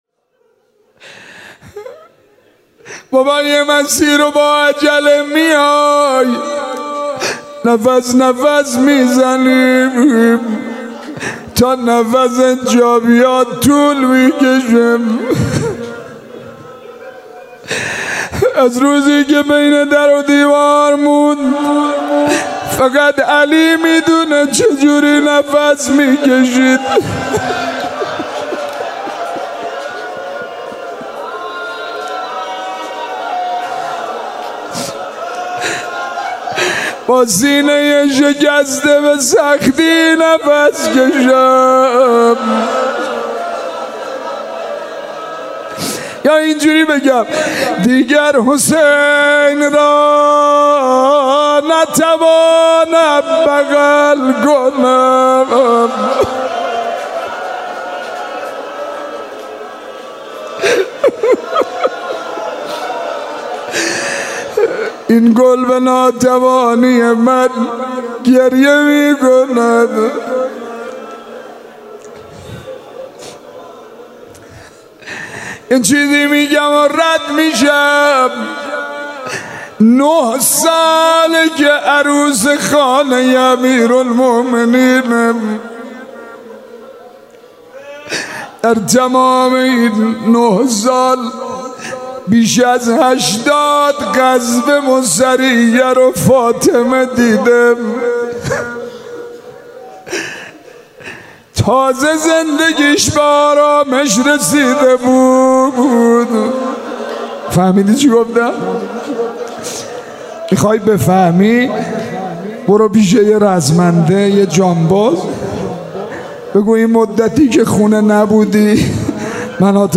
روضه حضرت زهرا(کمیل) 1398-10-13